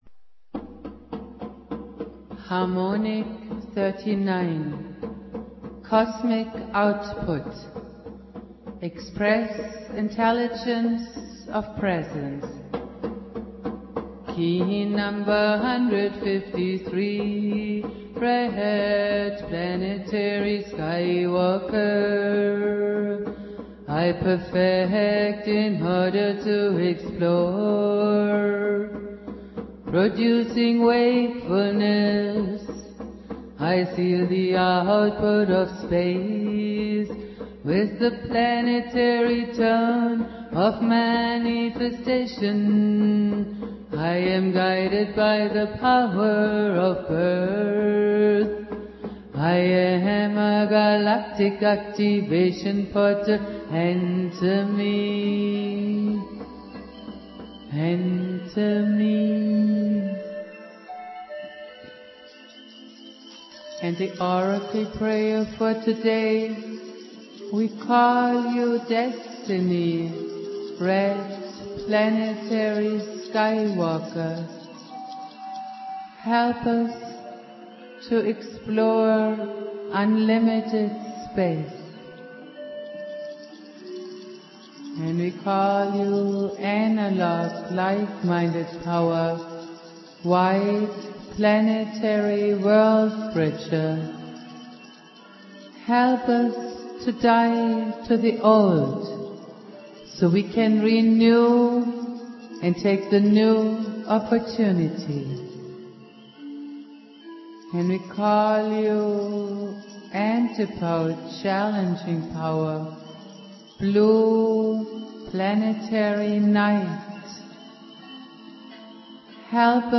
The Dreamspell Prayer for Today
Jose Arguelles - Valum Votan playing flute.
Prayer